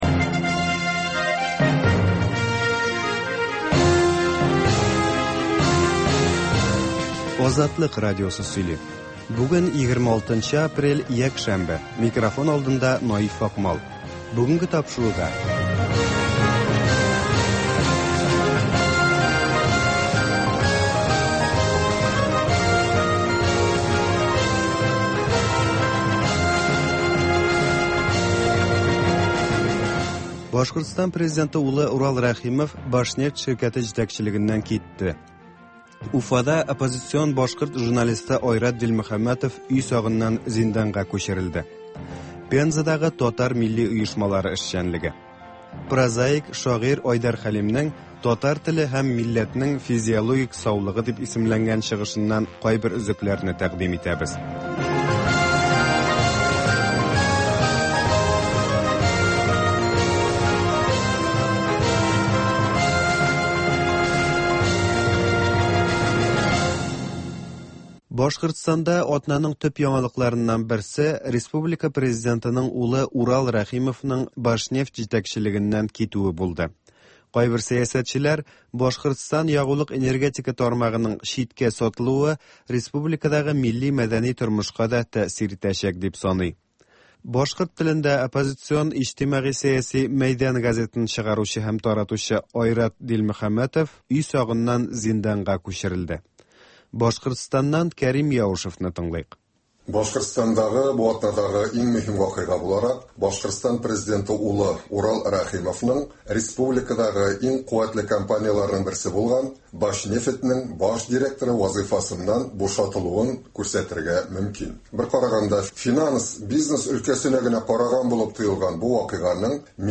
Азатлык узган атнага күз сала - башкортстаннан атналык күзәтү - татар дөньясы - түгәрәк өстәл артында сөйләшү